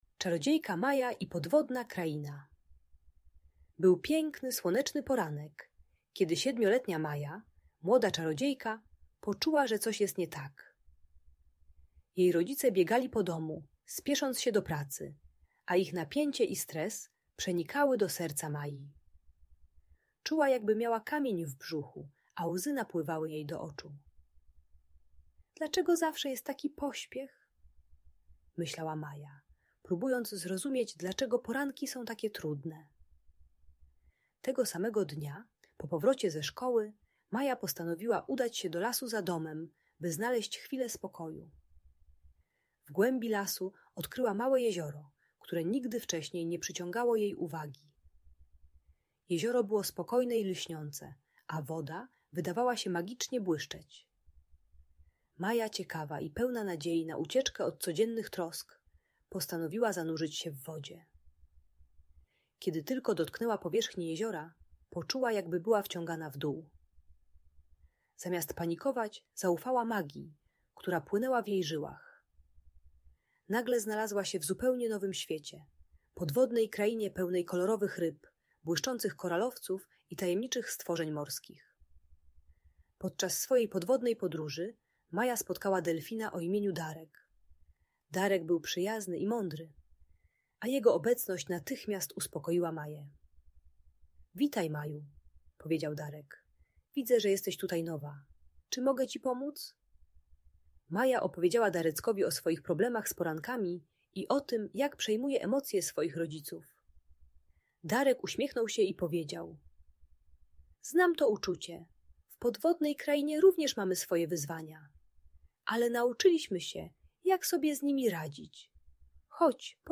Historia Czarodziejki Maji i Podwodnej Krainy - Emocje rodzica | Audiobajka